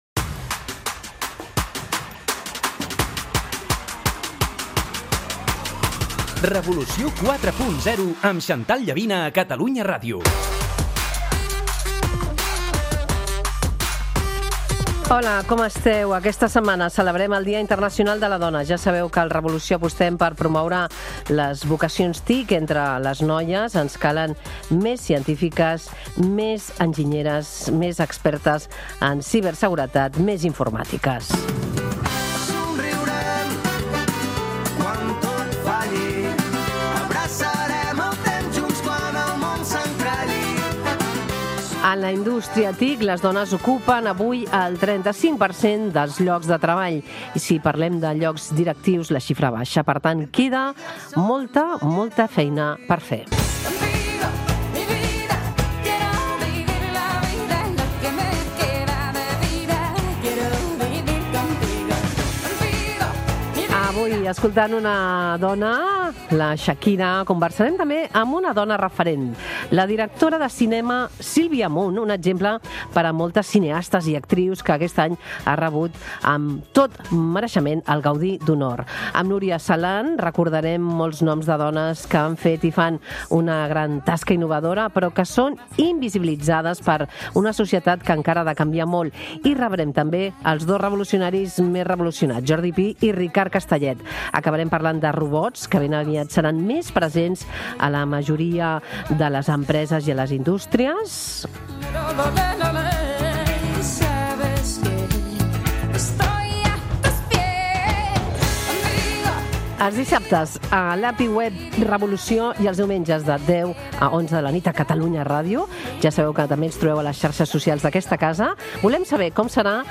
L'entrevista central és amb la directora de cinema Sílvia Munt, que rep el Gaudí d'Honor i reflexiona sobre creativitat, mirada femenina i els obstacles que ha trobat com a directora.